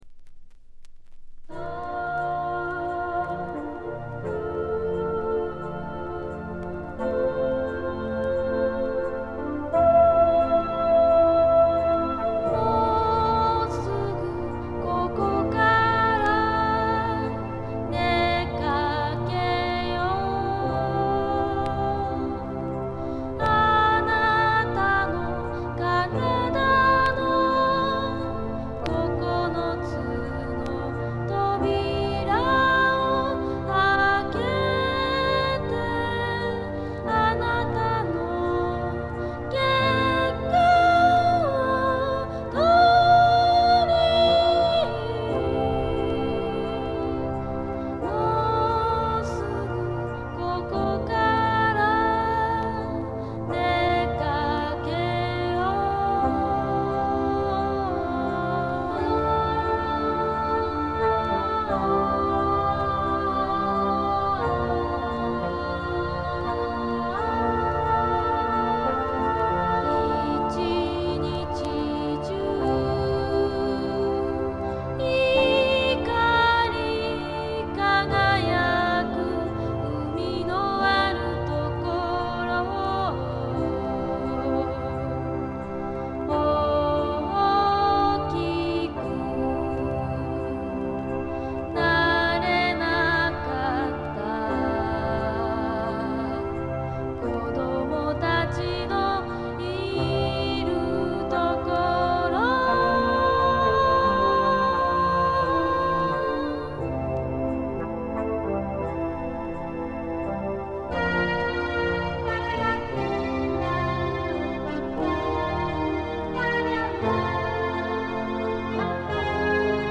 B1序盤にプツ音4回ほど。
試聴曲は現品からの取り込み音源です。